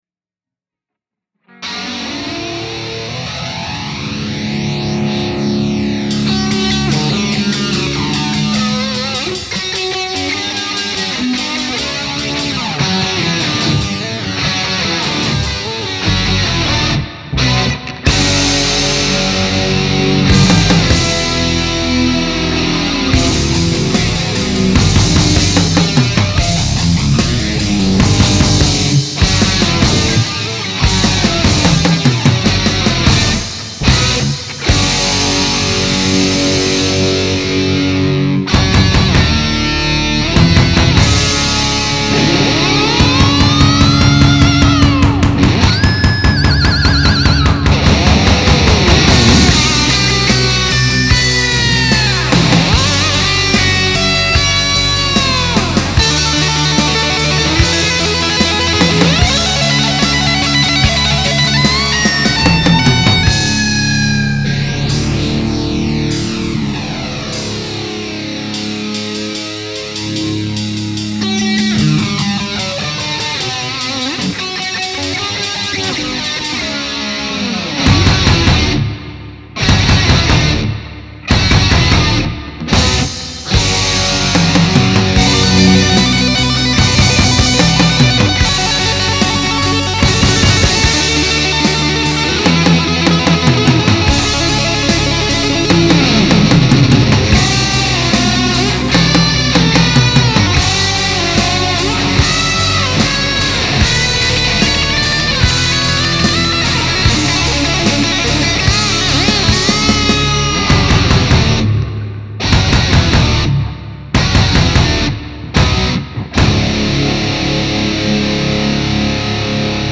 Instrumental for instructional purposes only
Re-arranged & performed in the style of Van Halen
guitar
bass
This is the VH style JFRocks version